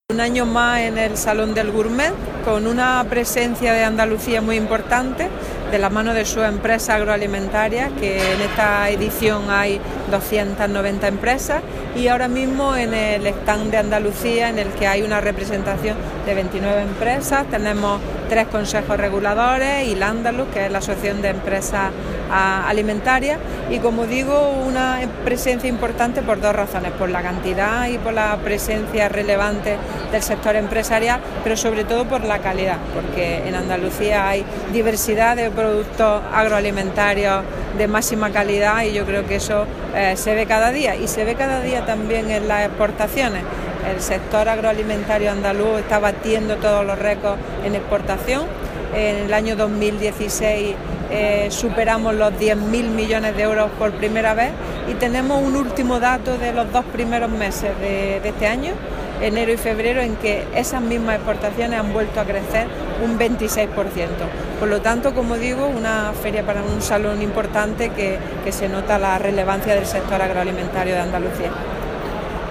Declaraciones de Carmen Ortiz sobre el Salón de Gourmets 2017 y exportaciones agroalimentarias andaluzas